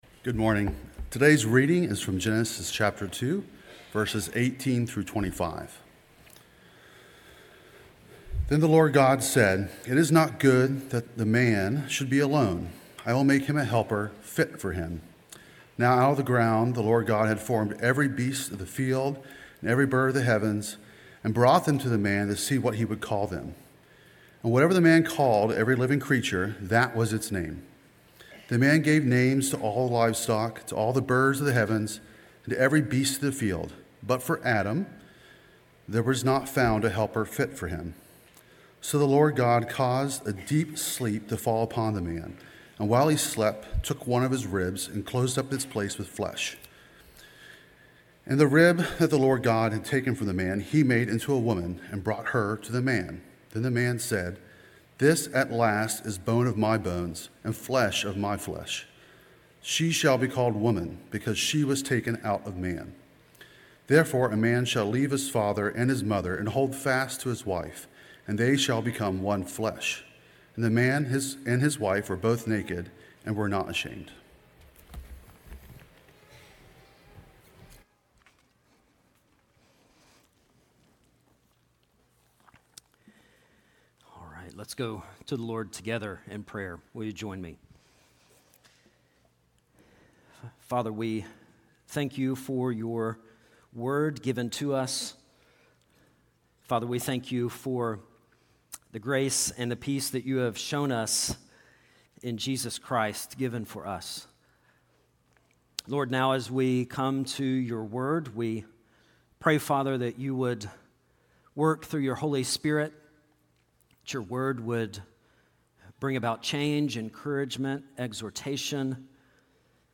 sermon8.10.25.mp3